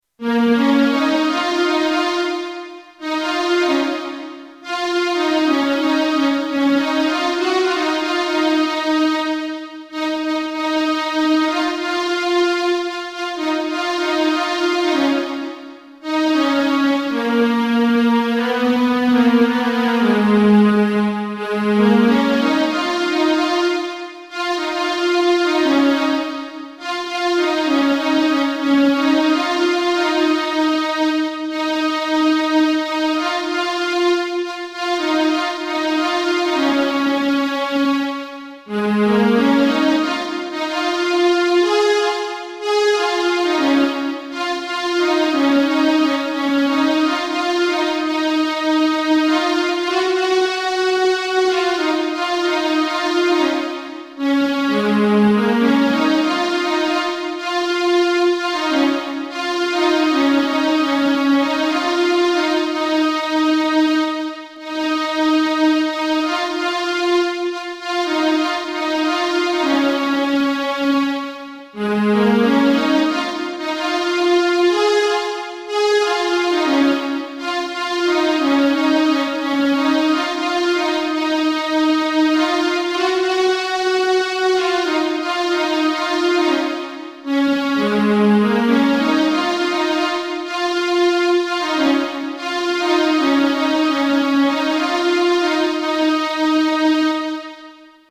First Sindhi Instrumental CD